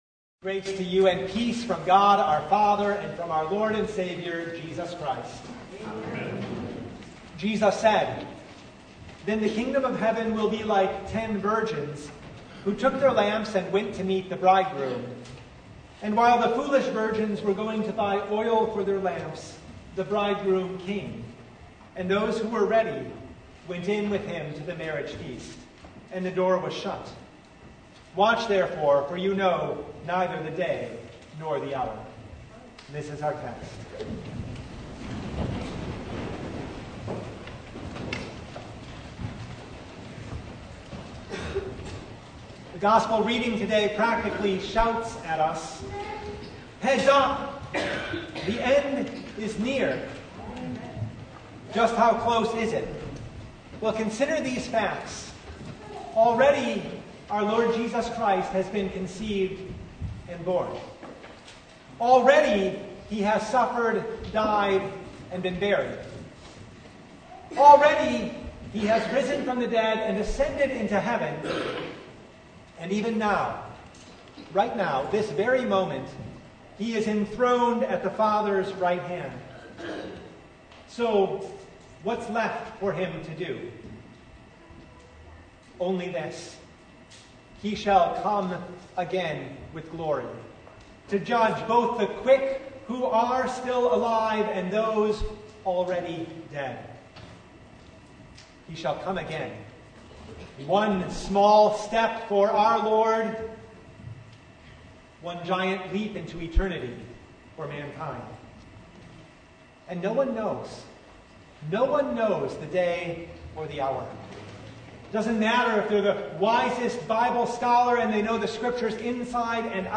Matthew 25:1-13 Service Type: Sunday Jesus shall come again as the Bridegroom.